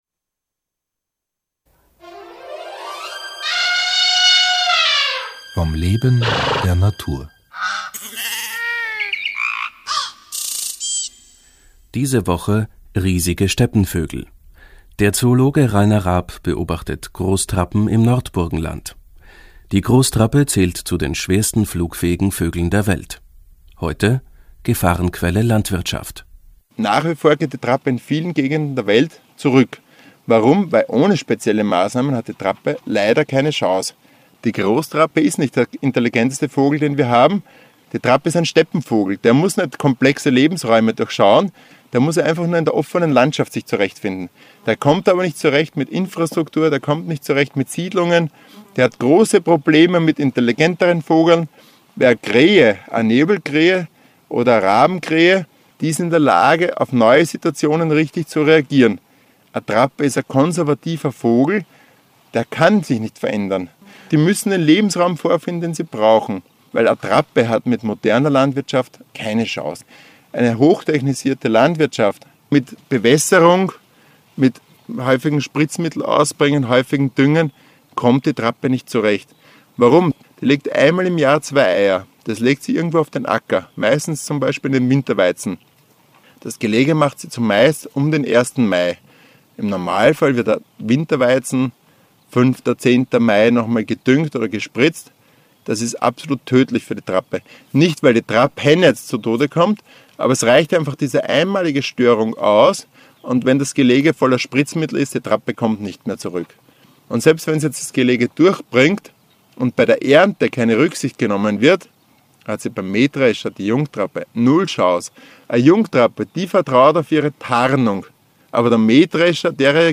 Interviews
broadcasted on the radio